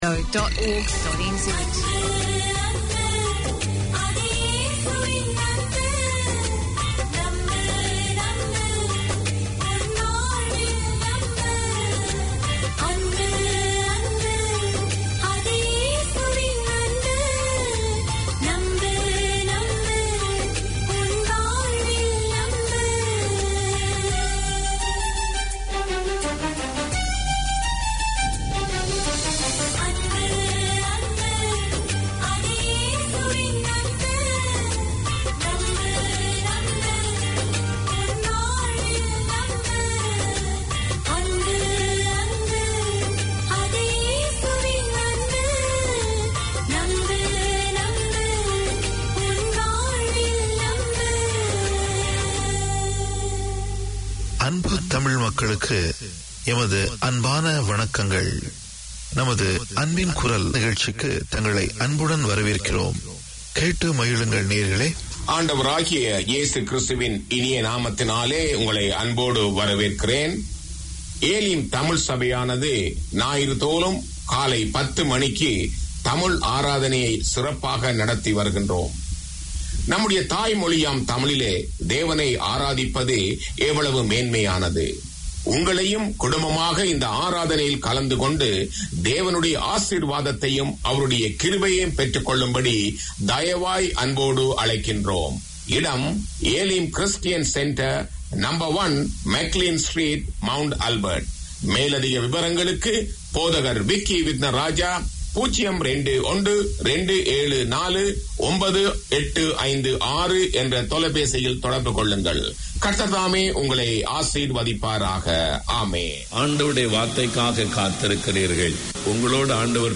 Religious discourses, music, interviews and chats on Hindu religion and culture feature in this 30 minute weekly programme from the NZ Hindu Temple Society. Alayam promotes dualism and understanding of the Vedas, provides Q & A on Vethantham, interviews with Sadhus from overseas and Vinayagar, Murugan, Siva, Vishnu and Amman songs are aired regularly.